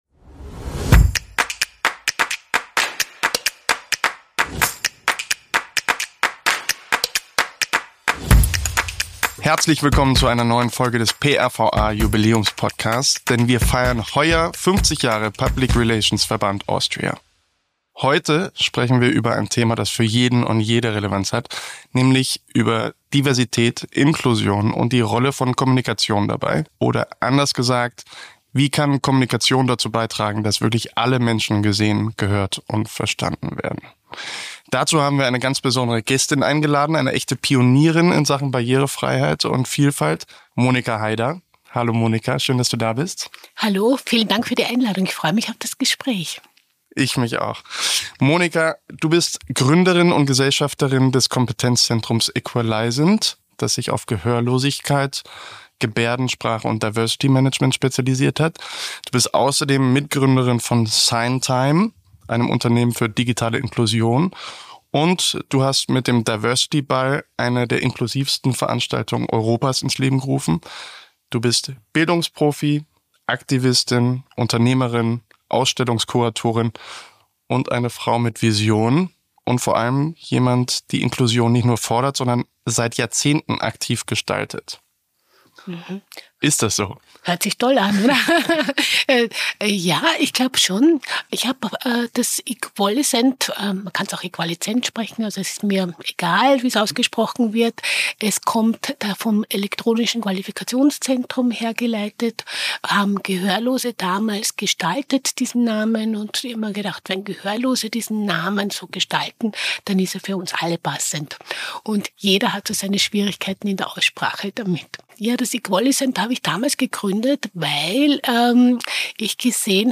Ein Gespräch über Haltung, Repräsentation und die Verantwortung der Kommunikationsbranche, gesellschaftlichen Wandel mitzugestalten – hörenswert für alle, die Kommunikation inklusiver denken (und machen) wollen.